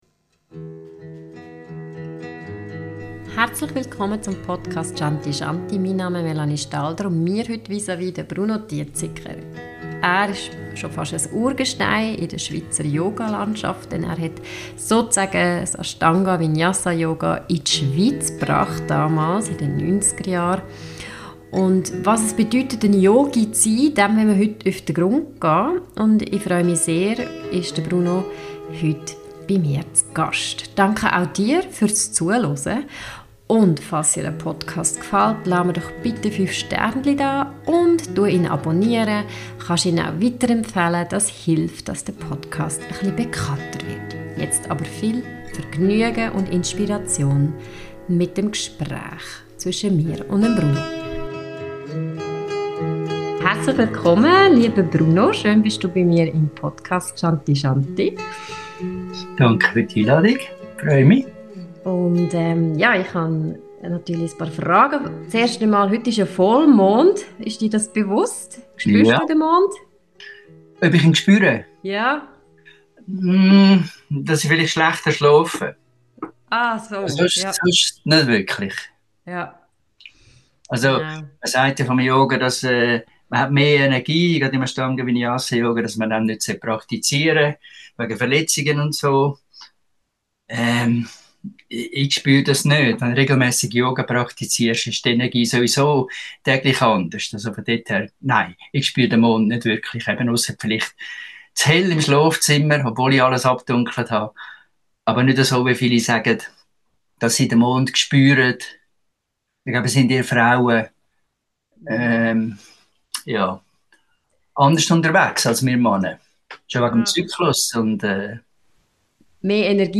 Wie viel Yoga steckt noch im Yoga - Interview